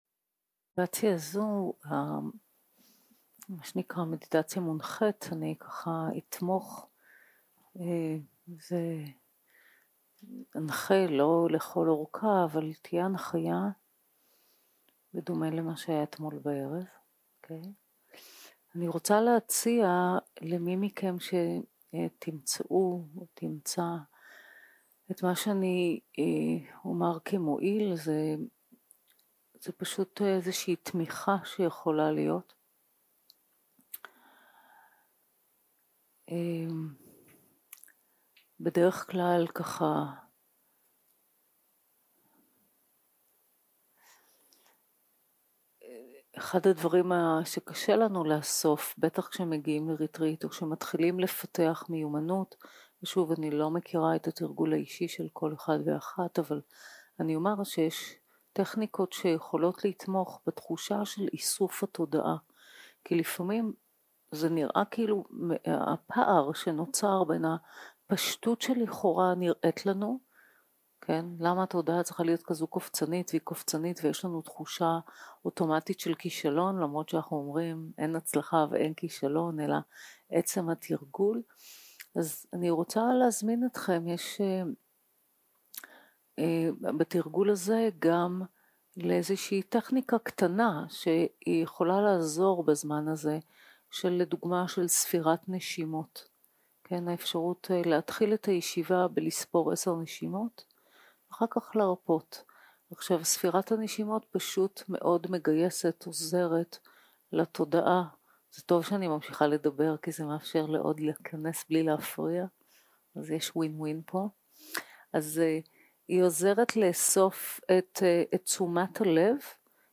יום 2 - הקלטה 2 - צהרים - מדיטציה מונחית - ריכוז בנשימה
Guided meditation